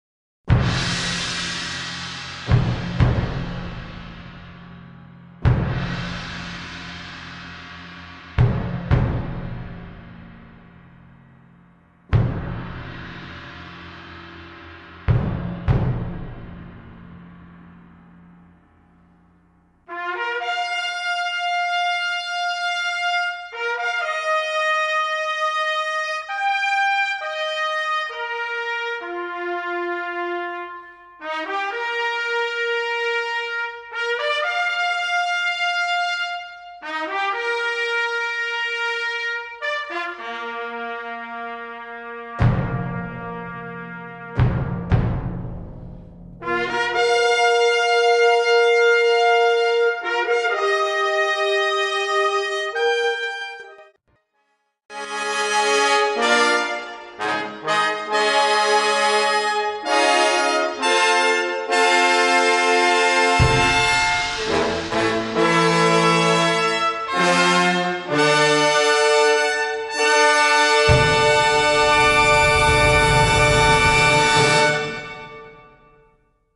Gattung: für Blechbläserensemble
Besetzung: Ensemblemusik Blechbläserensemble